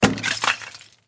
На этой странице собраны разнообразные звуки, связанные с кирпичами: от стука при строительстве до грохота падения.
Удар такой мощи, что облицовочные кирпичи отлетели